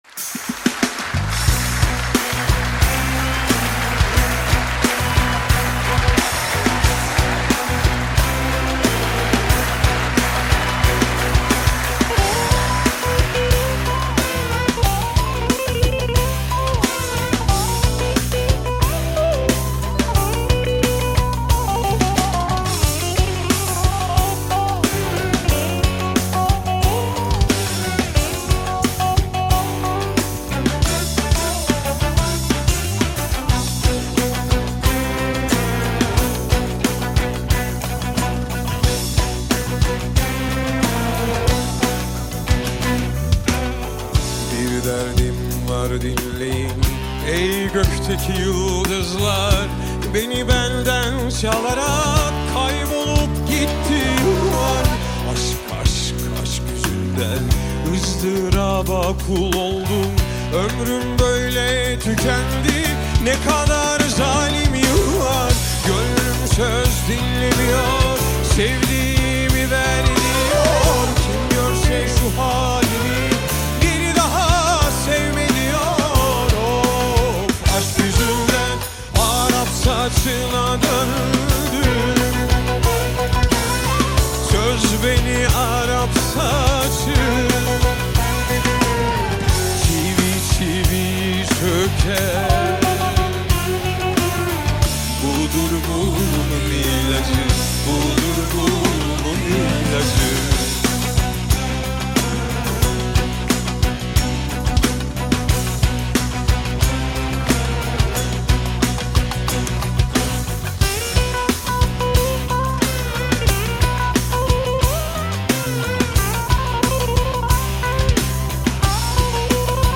سبک عربچا